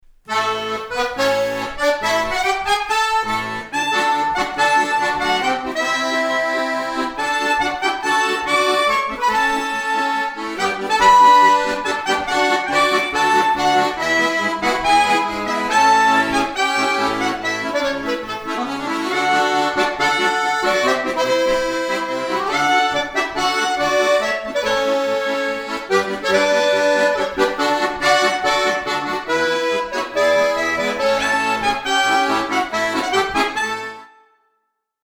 Je hoort 2x hetzelfde liedje, maar klinkt het ook hetzelfde?